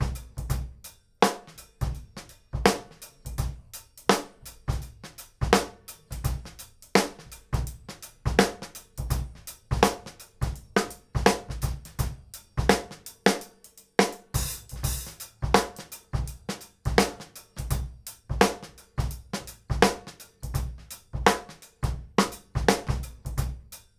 GROOVE 190IR.wav